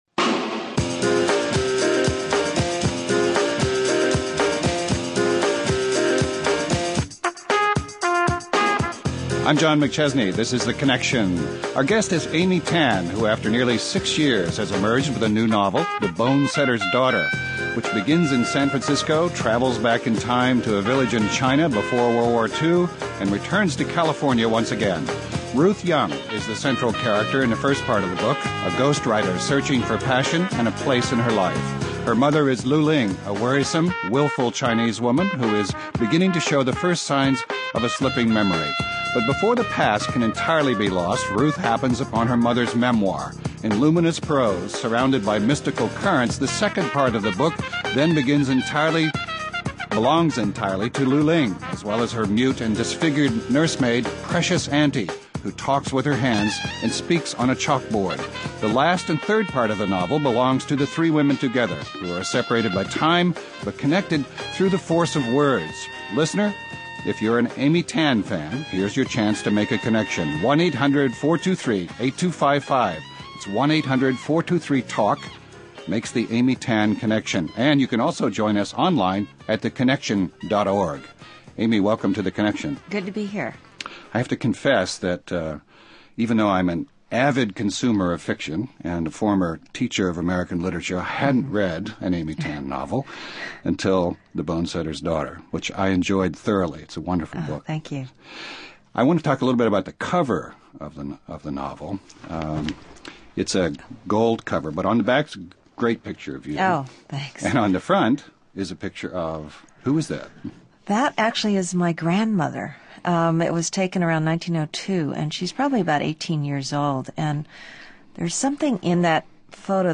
So Connection listeners, what’s YOUR opinion? What do you think about George Bush’s address Tuesday night?